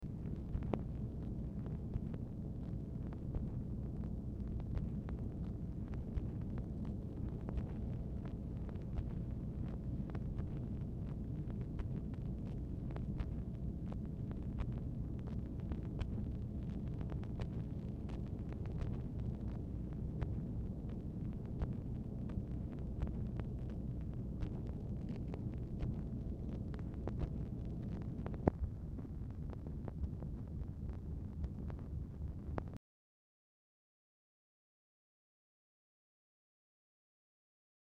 MACHINE NOISE
Format Dictation belt
LBJ Ranch, near Stonewall, Texas
Specific Item Type Telephone conversation